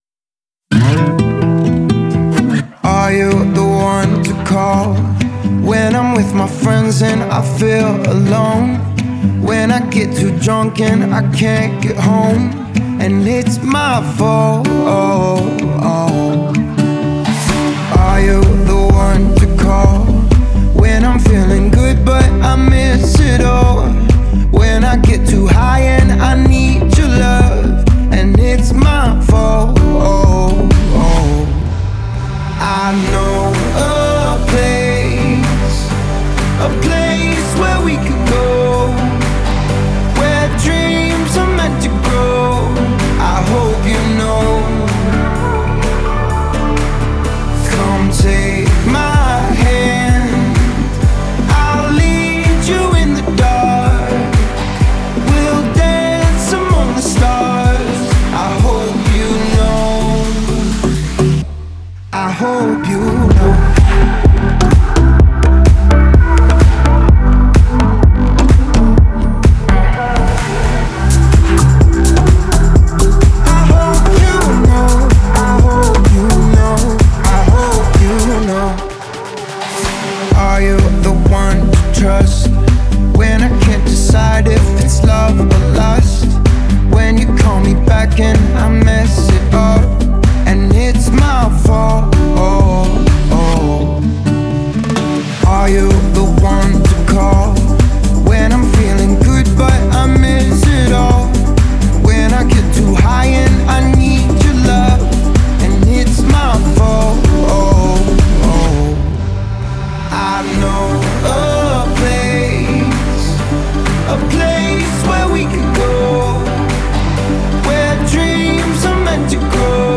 это мелодичная электронная композиция